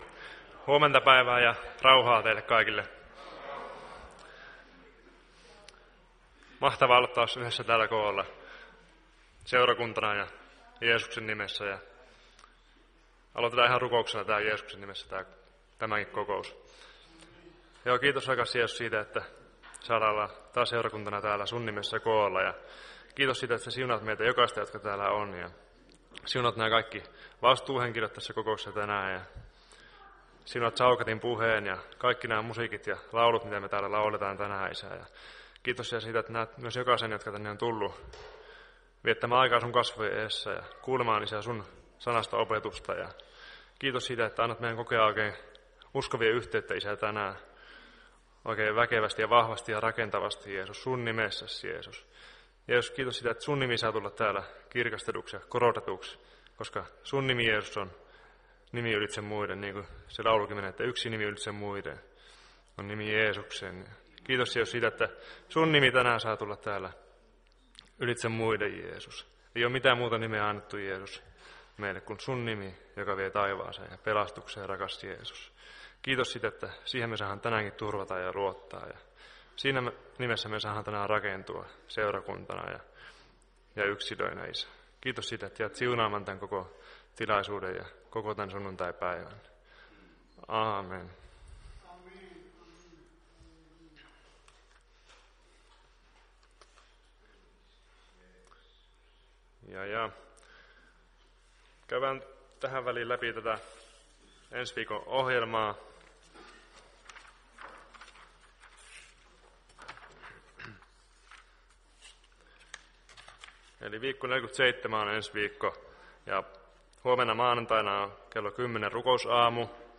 Päiväkokous 17.11.2019